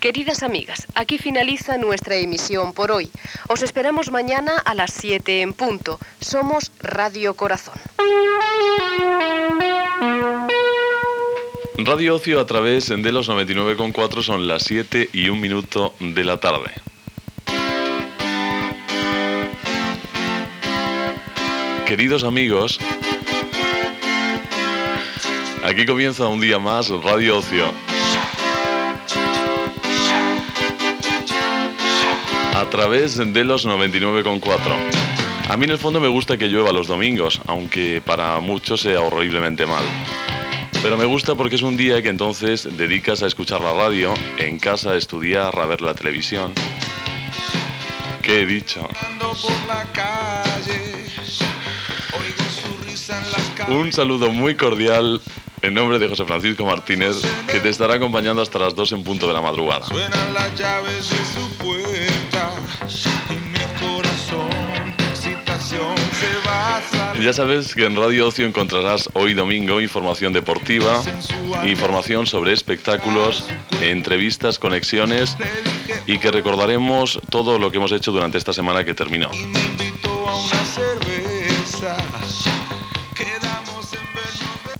df224b7caa24b225513fd57488b756e07ec3145b.mp3 Títol Radio Ocio Emissora Radio Ocio Titularitat Privada local Descripció Final de Radio Corazón i inici de la programació de Radio Ocio.